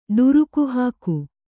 ♪ ḍuruku hāku